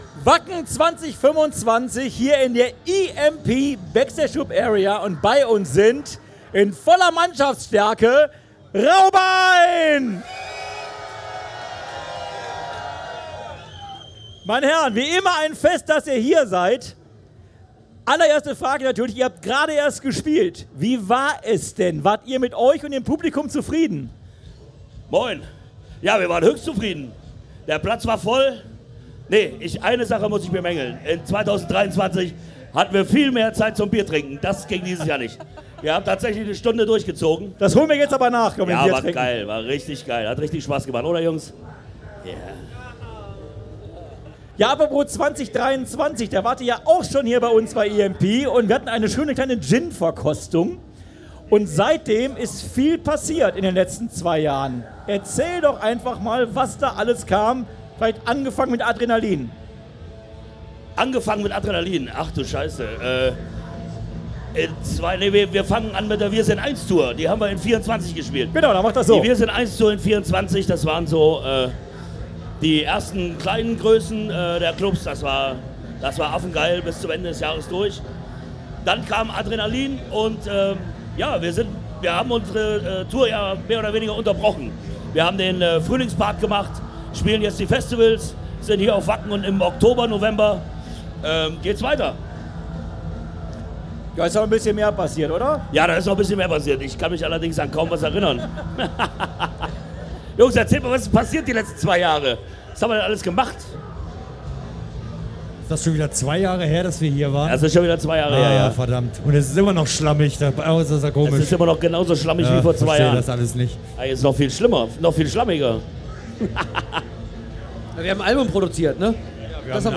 Wacken 2025 Special - Rauhbein - Live aus der EMP Backstage Club Area